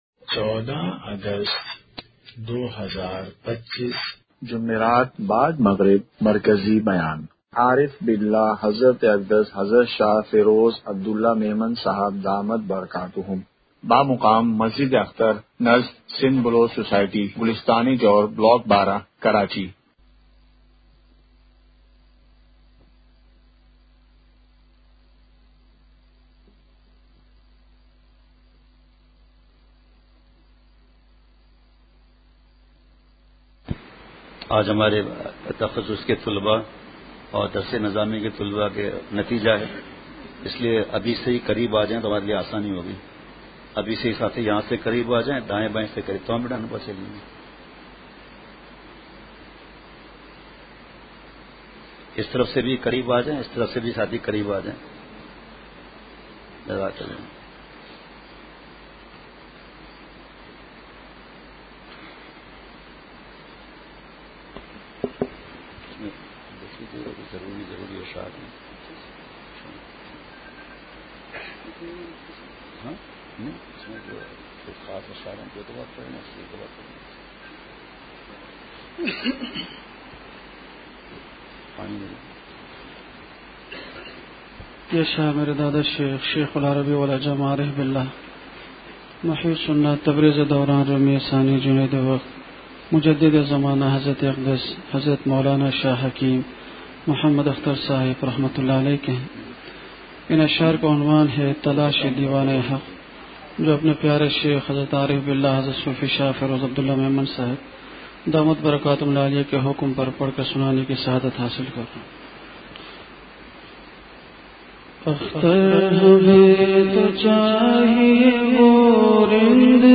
مرکزی بیان ۱۴ / اگست ۲۵ء:پاکستان کا صحیح شکر کیا ہے ؟